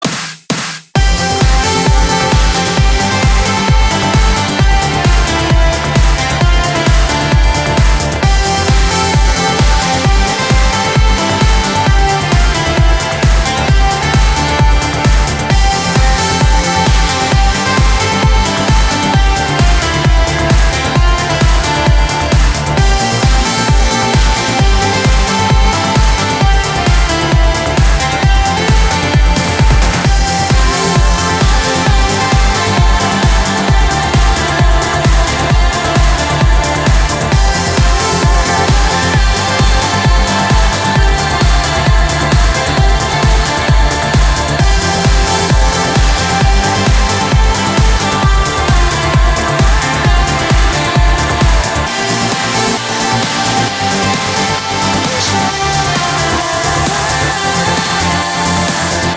• Качество: 320, Stereo
громкие
dance
Electronic
электронная музыка
без слов
progressive trance
Trance